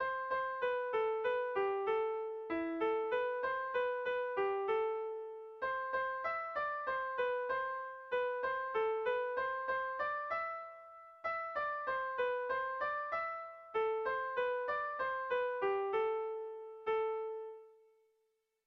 Erlijiozkoa
Baxenabarre < Euskal Herria
ABD